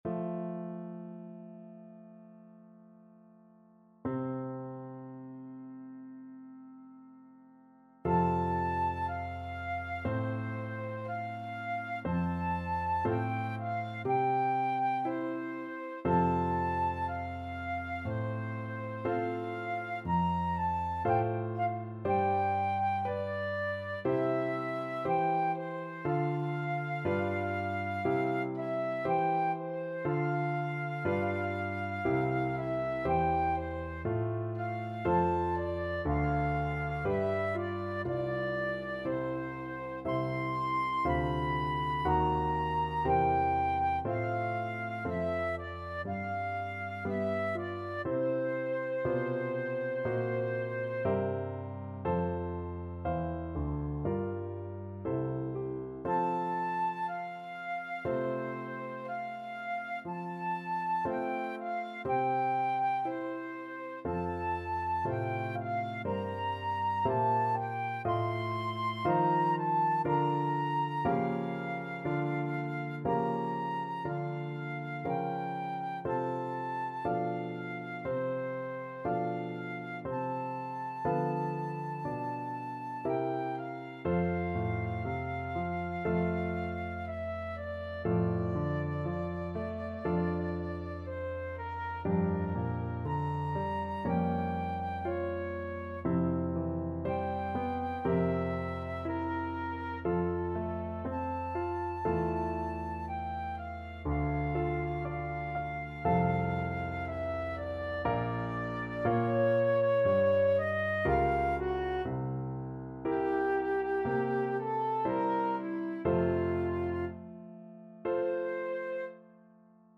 Flute version
2/4 (View more 2/4 Music)
~ = 100 Adagio =c.60